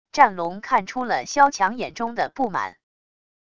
战龙看出了萧强眼中的不满wav音频生成系统WAV Audio Player